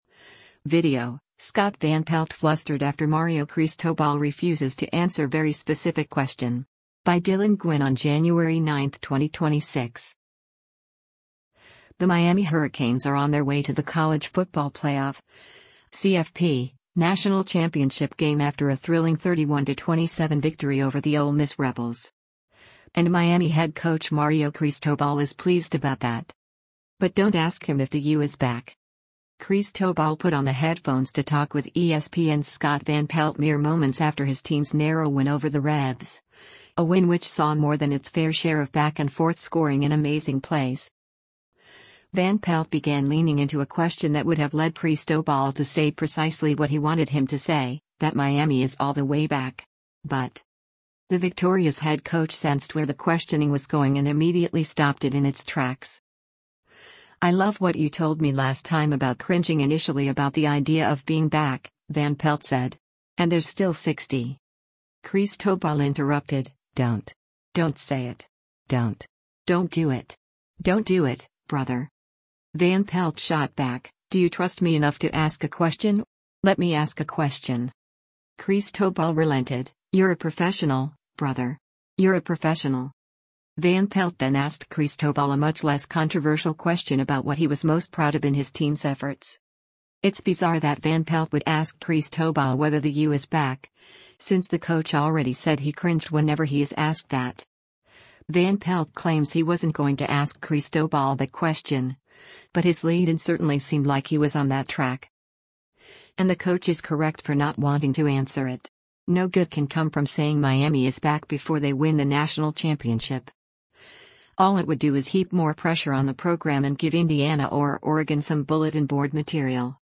Cristobal put on the headphones to talk with ESPN’s Scott Van Pelt mere moments after his team’s narrow win over the Rebs, a win which saw more than its fair share of back-and-forth scoring and amazing plays.